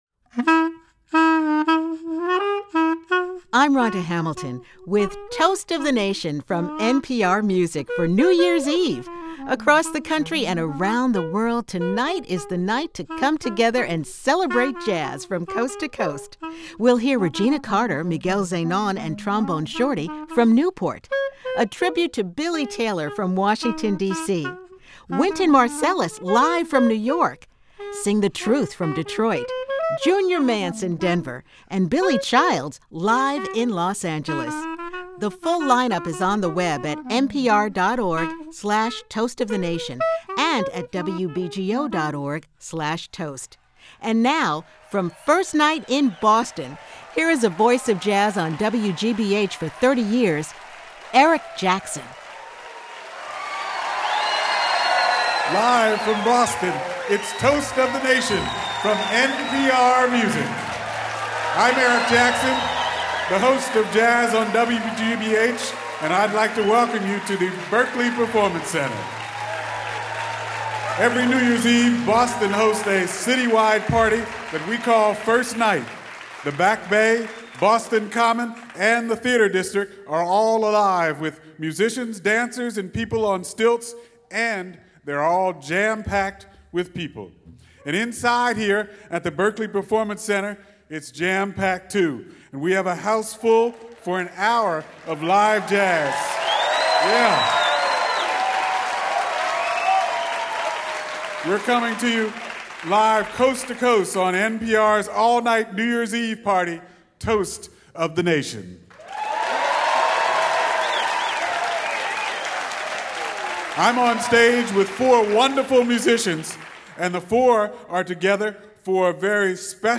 drums
bass) for a New Year's Eve party at his alma mater.